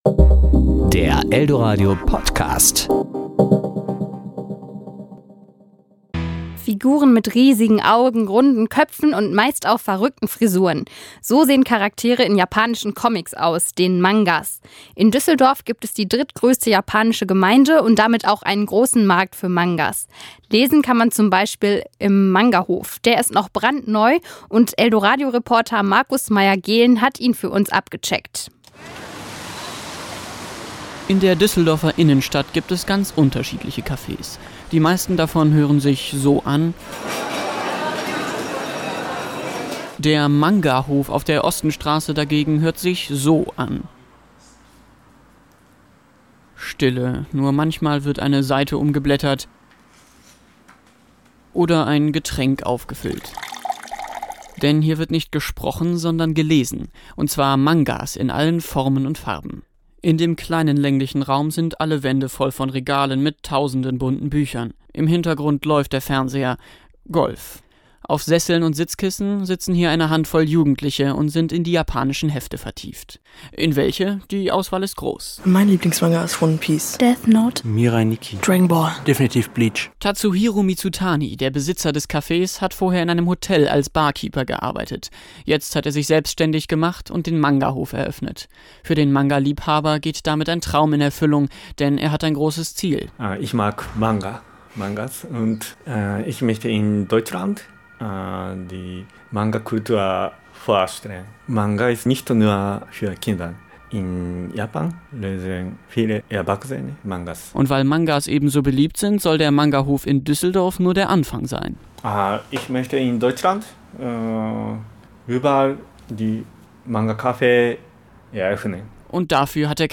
Besuch im Manga-Café